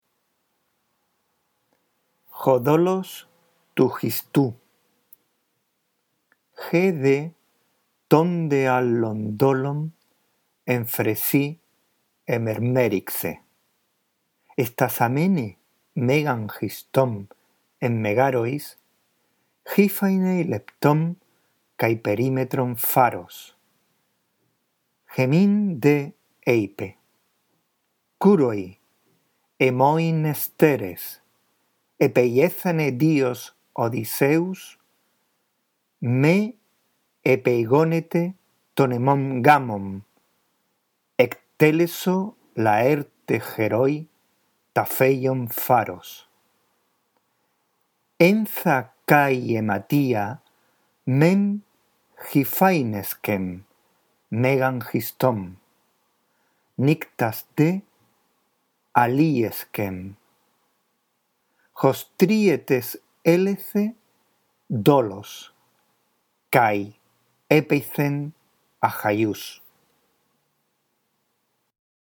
La audición de este archivo te ayudará en la práctica de la lectura del griego: